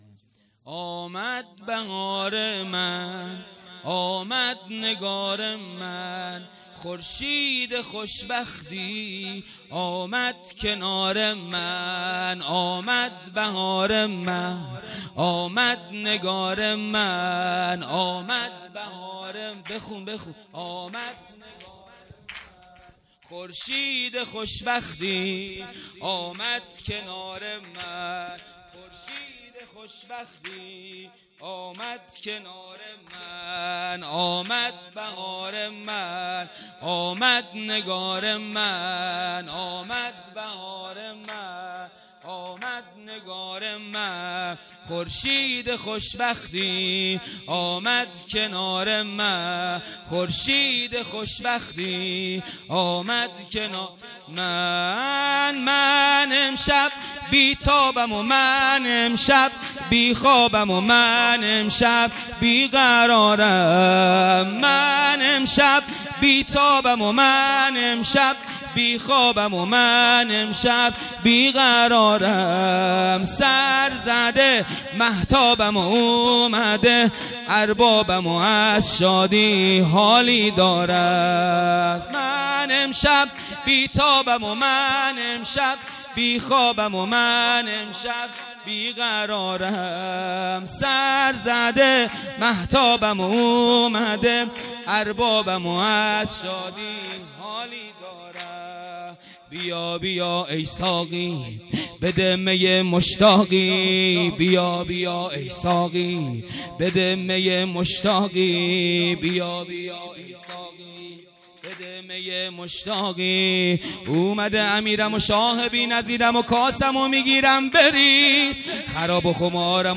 خیمه گاه - هیئت ذبیح العطشان کرمانشاه - ولادت حضرت علی اکبر(ع) - سرود
جلسه هفتگی -ولادت حضرت علی اکبر(ع)
هیئت ذبیح العطشان کرمانشاه